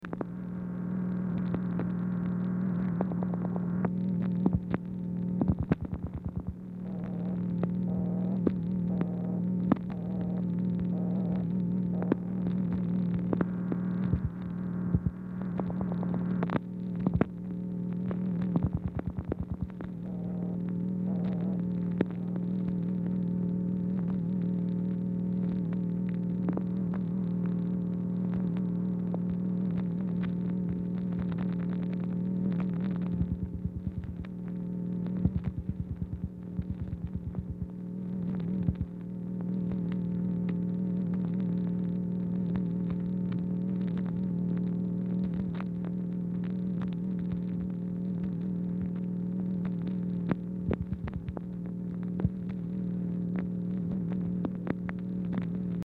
Telephone conversation # 539, sound recording, MACHINE NOISE, 12/18/1963, time unknown | Discover LBJ
Format Dictation belt
Specific Item Type Telephone conversation